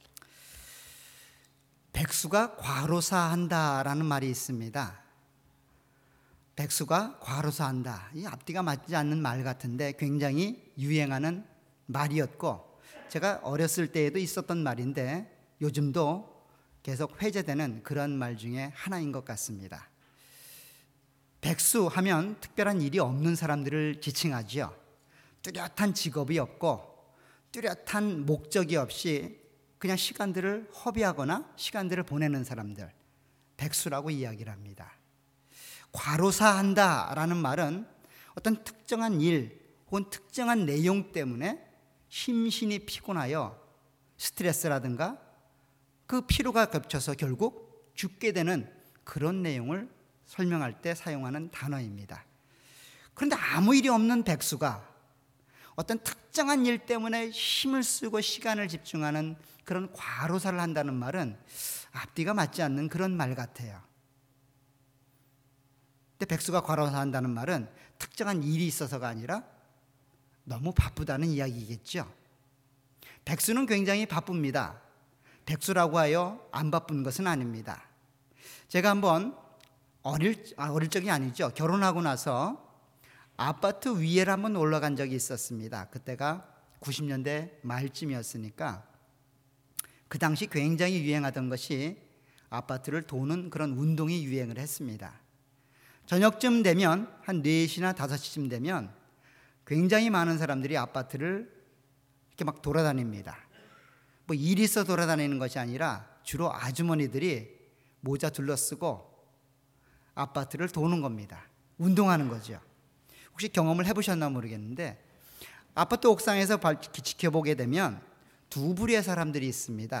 All Sermons
Series: 주일예배.Sunday